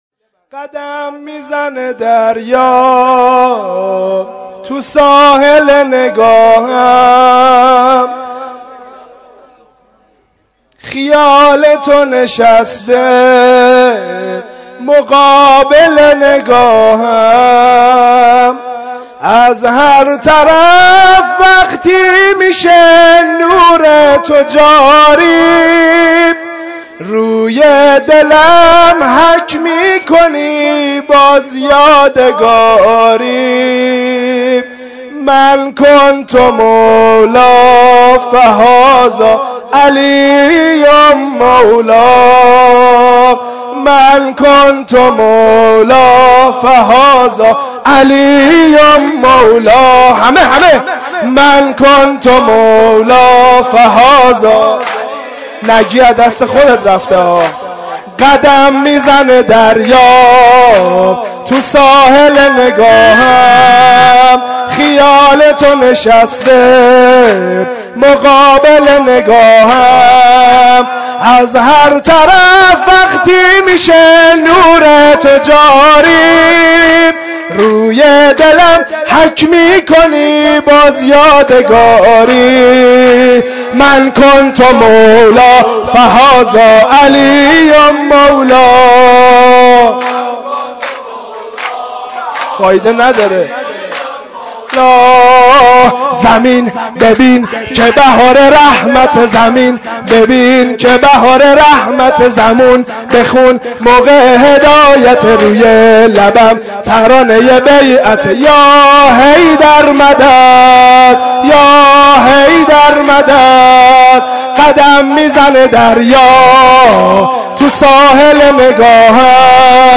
جشن عید غدیر؛ شب 18 ذی الحجه 1429؛ قسمت چهارم